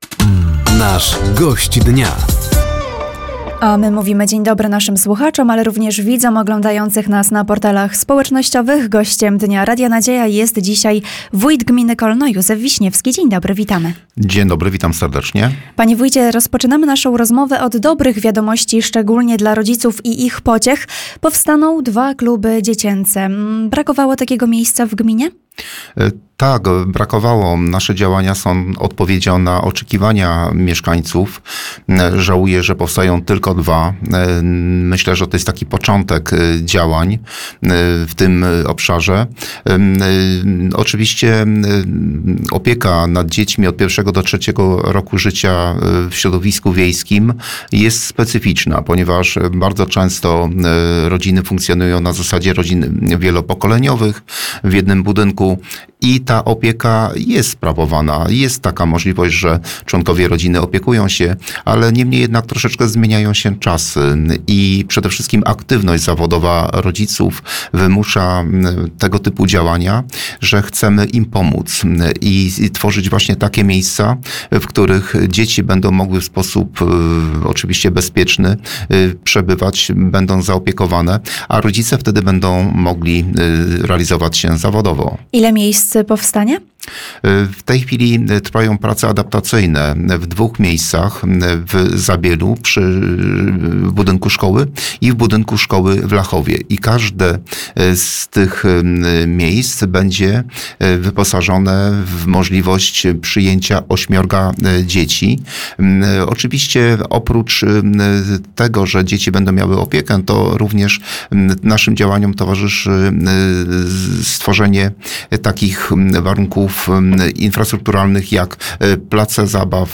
Powstanie klubów dziecięcych w miejscowości Lachowo i Zabiele, bieżące inwestycje drogowe oraz trwające prace przy Izbie Pamięci rolnictwa i życia na wsi w Lachowie – to główne tematy rozmowy podczas audycji ,,Gość Dnia”. Studio Radia Nadzieja odwiedził wójt Gminy Kolno, Józef Wiśniewski.